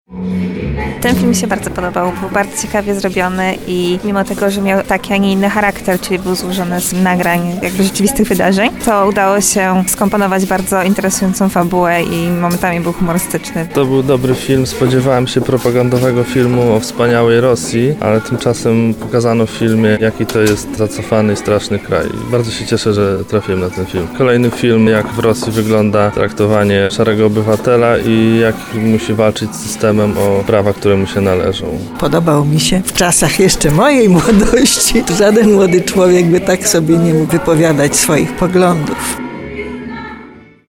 O wrażenia z pokazu zapytała nasza reporterka